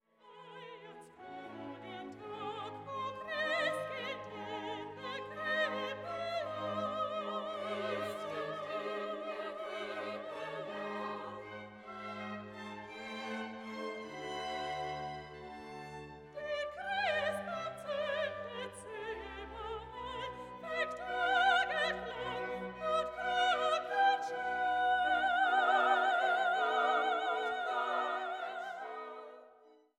für Sopran, Bariton, Frauenchor und Orchester